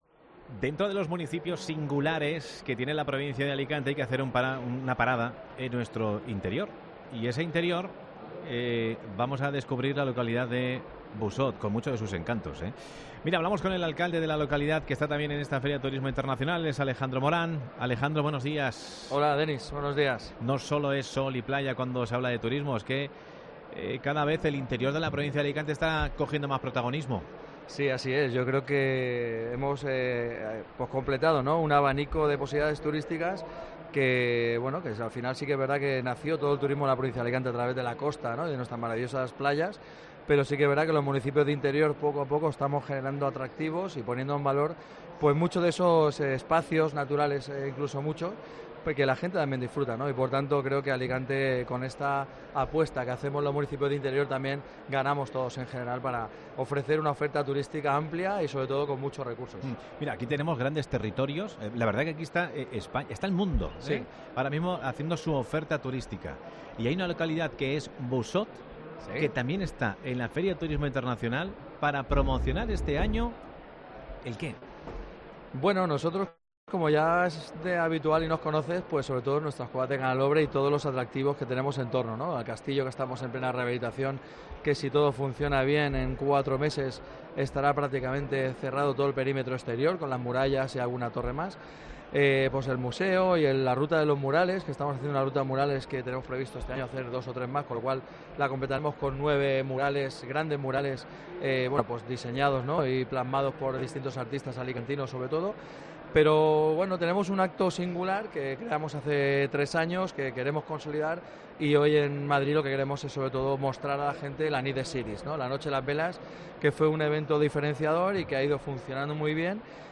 "Tenemos un proyecto para realizar una doble tirolina de 900 metros, seria la segunda o tercera más larga de España" Entrevista con Alejandro Morant, Alcalde de Busot